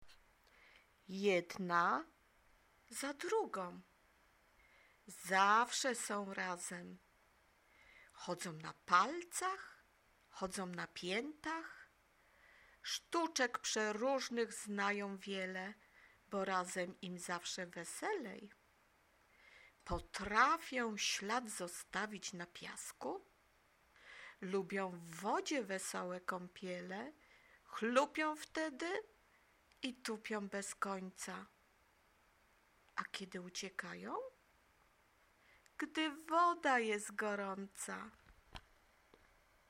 stopy-wiersz
stopy-wiersz.mp3